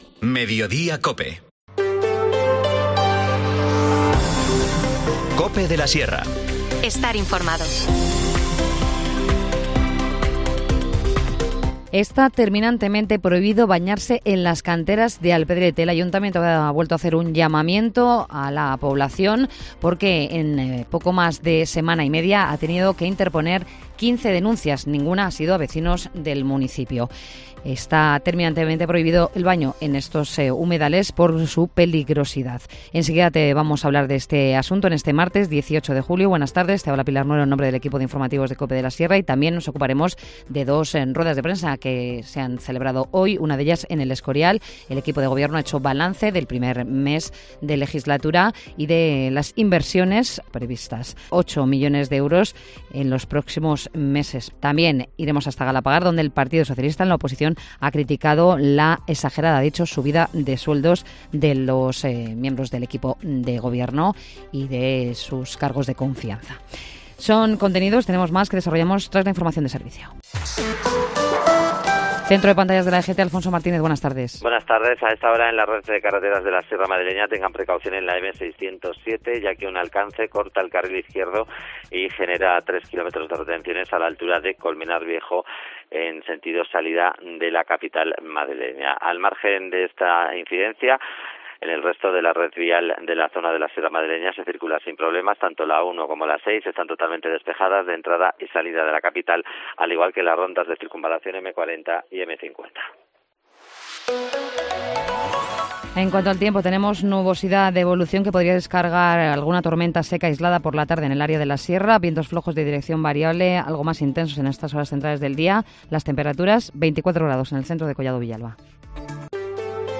INFORMACIÓN LOCAL